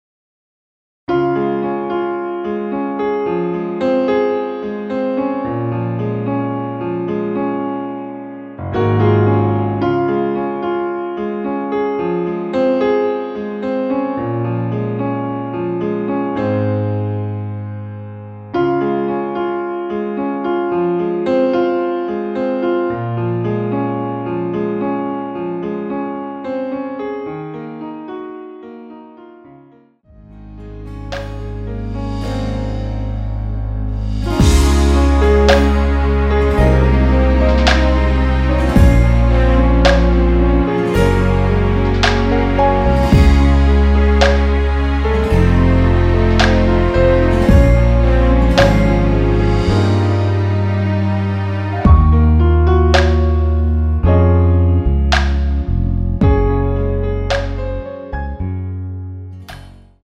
원키에서(+3)올린 MR입니다.(미리듣기 참조)
Db
앞부분30초, 뒷부분30초씩 편집해서 올려 드리고 있습니다.